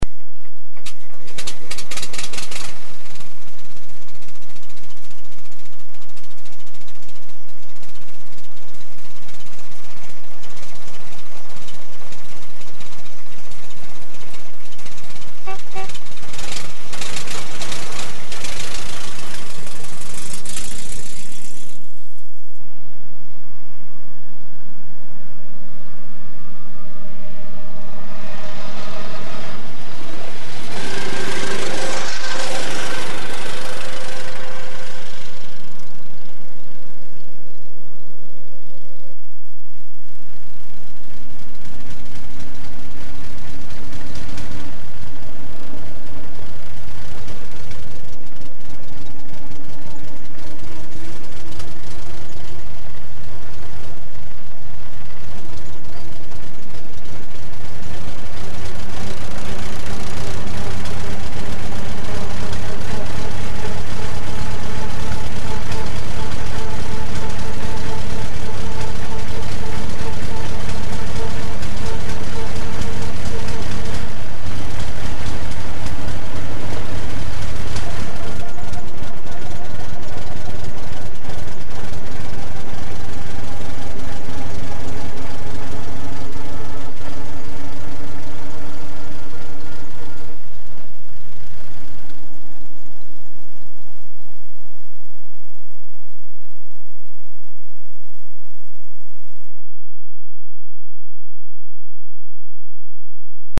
A jármű hangja általában hármas felosztásban hallható:
indulás, elhaladás, kocsiban ülve.
Panhard_B1.mp3